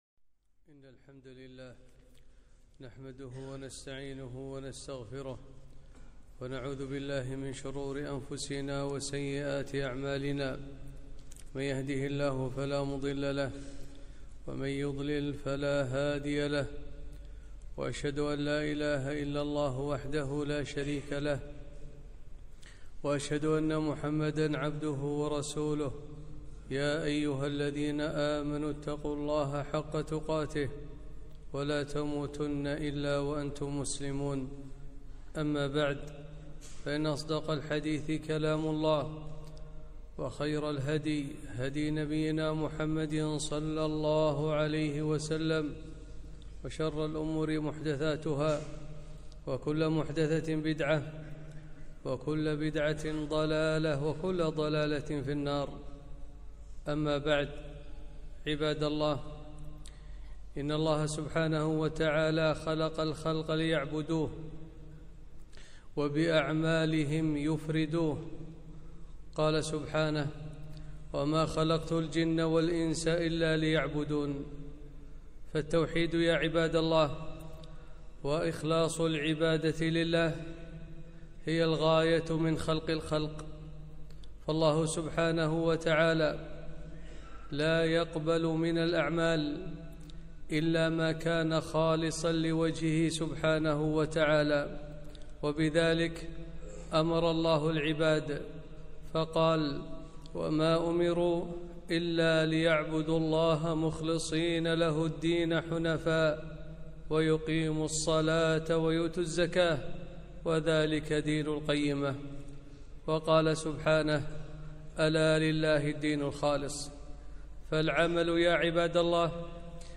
خطبة - الشرك الأصغر الرياء